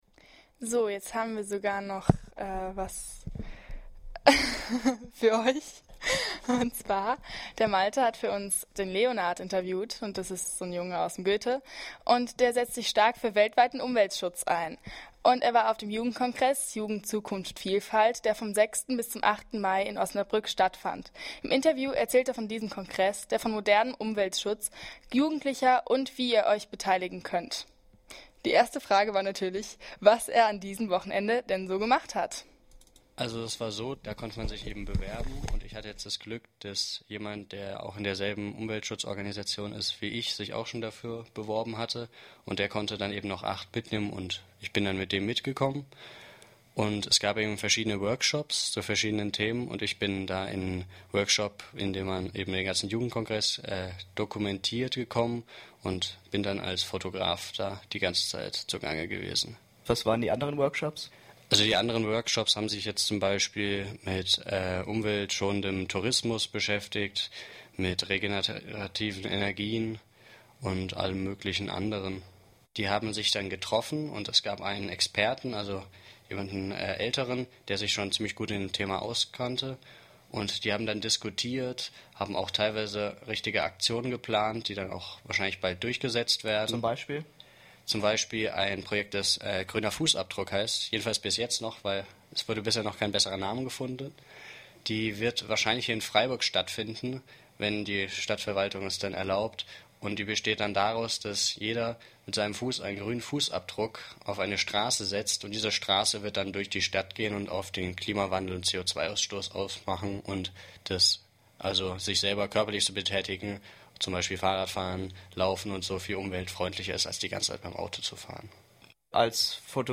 Interview mit einem Jugendlichen aus Freiburg zum Thema Umweltschutz im weitesten Sinne und zum Kongress Jugend-Zukunft-Vielfalt.